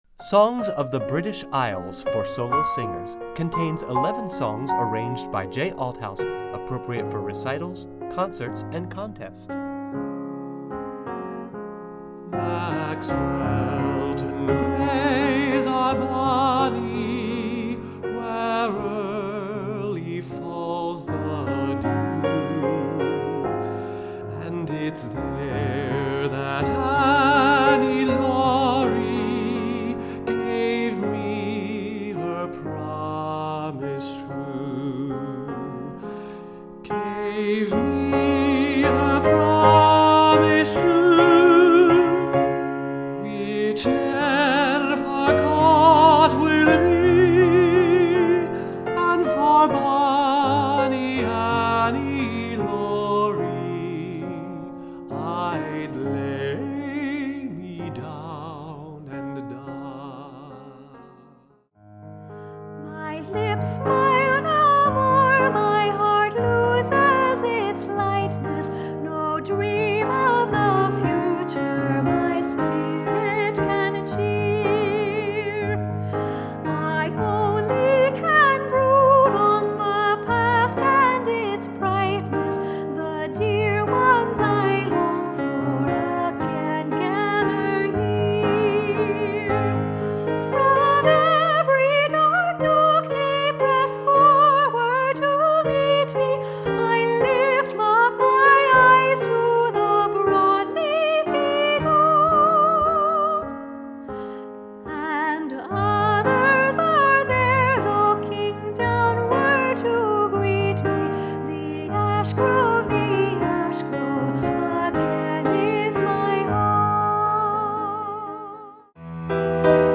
Voicing: Medium-Low Voice